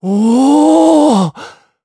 Zafir-Vox_Happy4_jp.wav